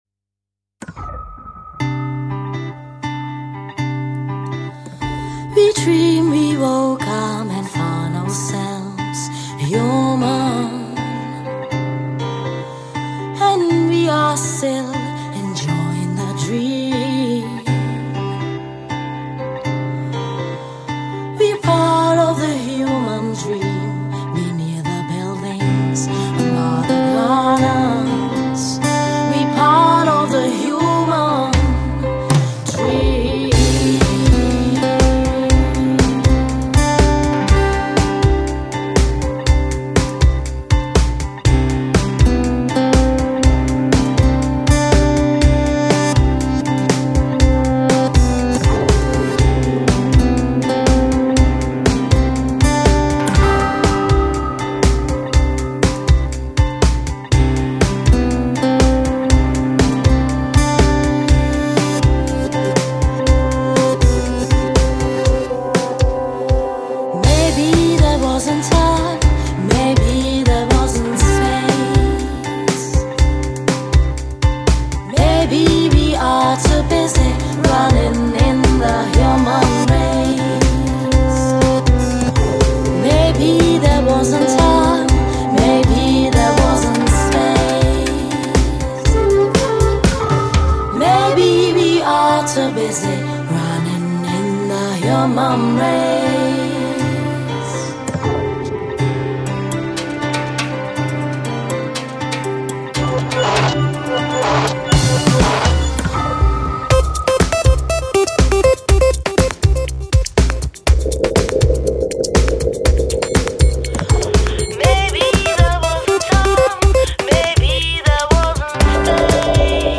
Žánr: Electro/Dance
svým sytým hlasem